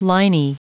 Prononciation du mot liney en anglais (fichier audio)
Prononciation du mot : liney